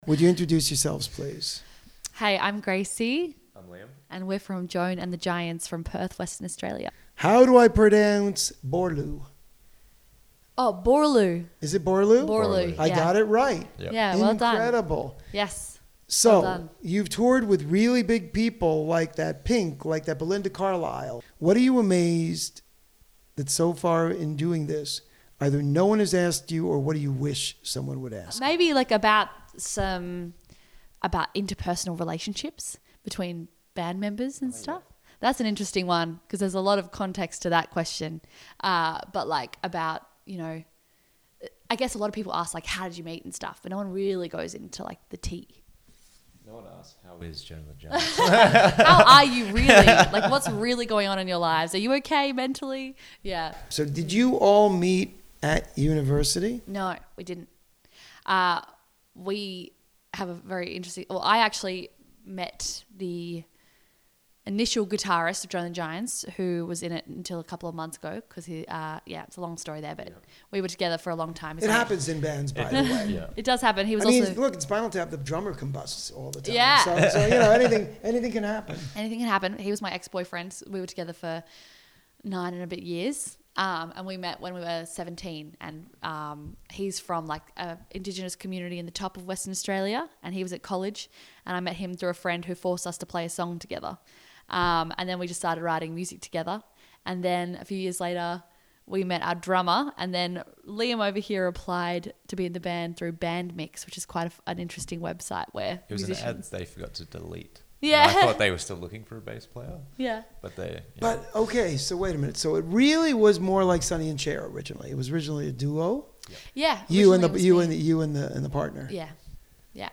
This Week's Interview (06/01/2025): Joan & The Giants LISTEN TO THE INTERVIEW